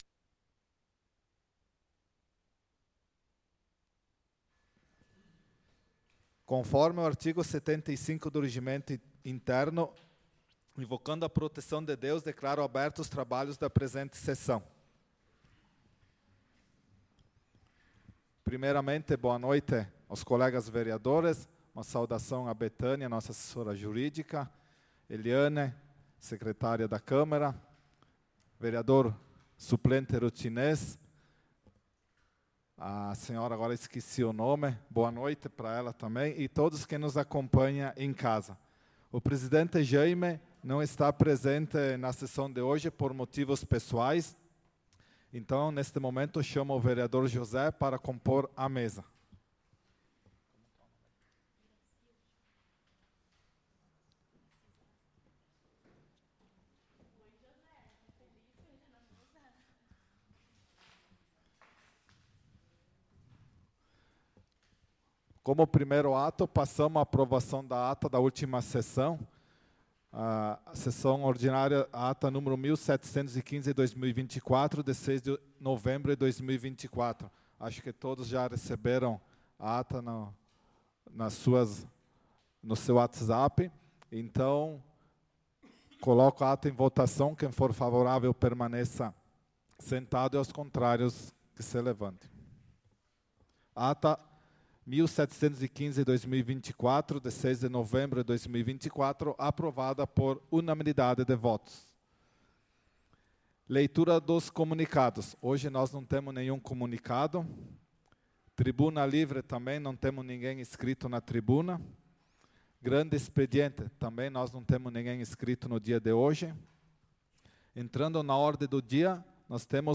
Sessão Ordinária do dia 13/11/2024